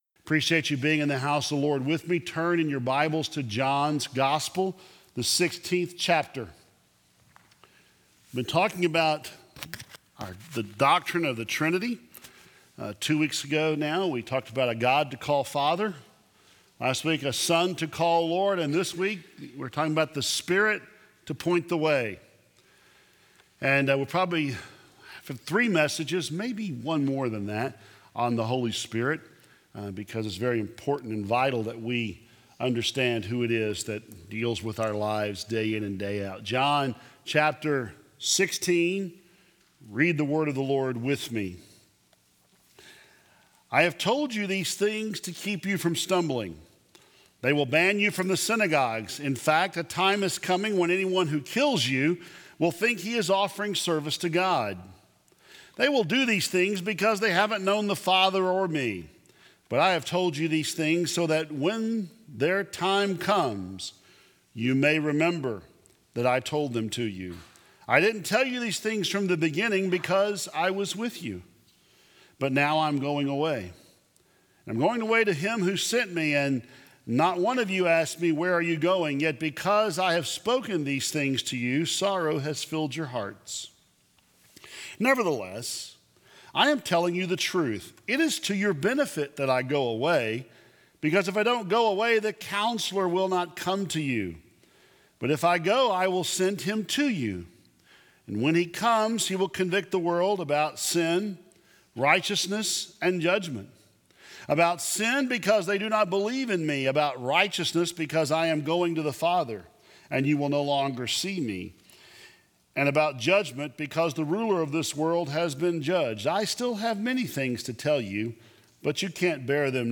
2016 Related Share this sermon